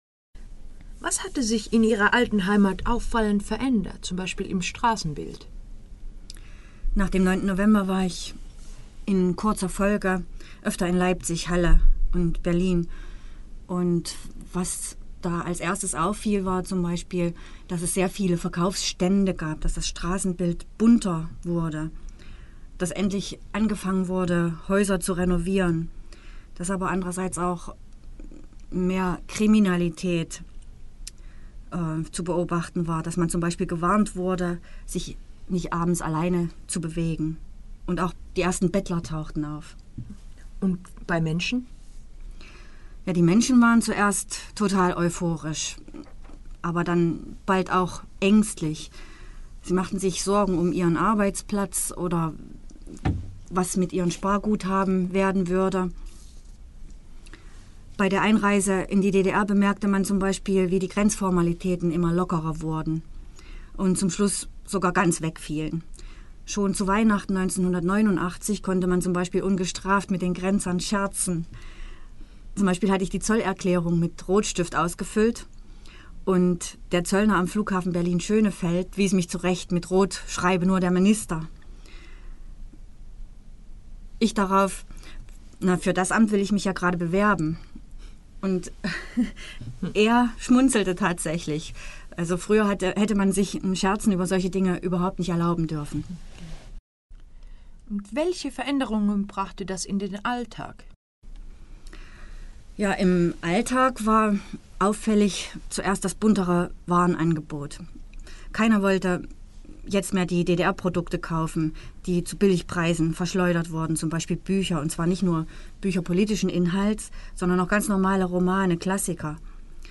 Das Interview Teil 2
Die Wende - Ein Gespräch